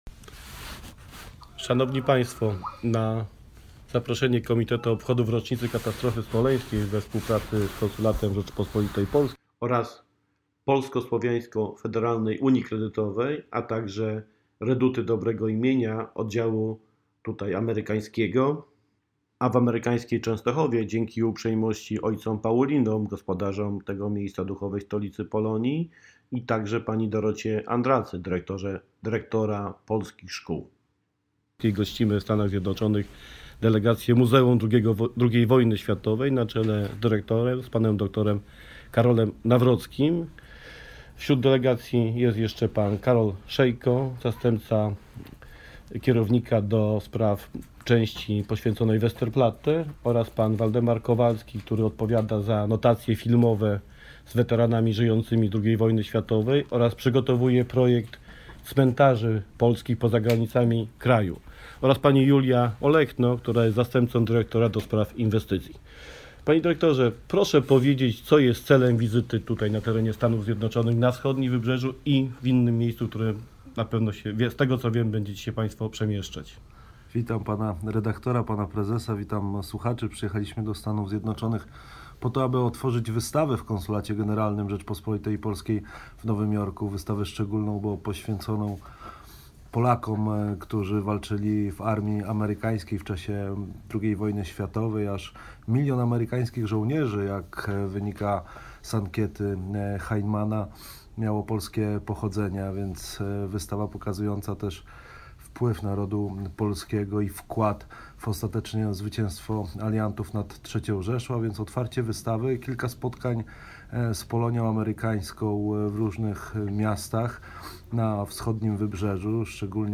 Wywiad z delegacji z Muzeum II Wojny Światowej